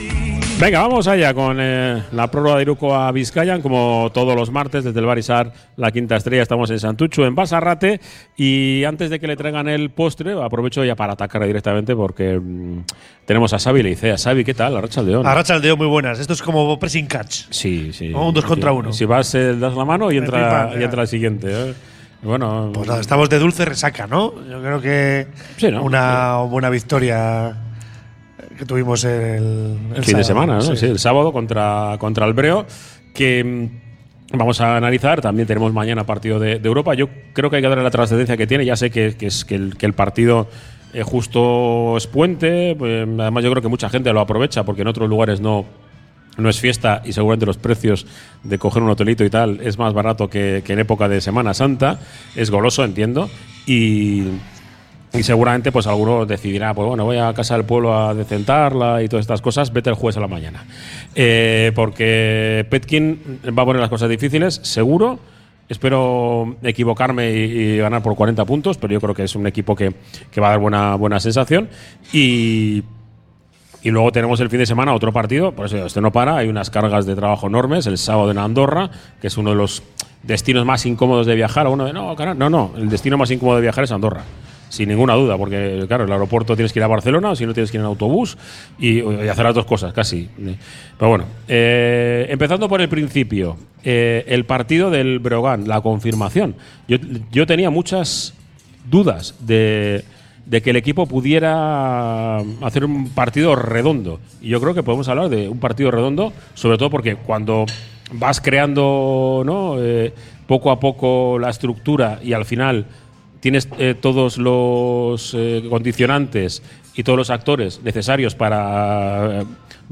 Desde el Bar Izar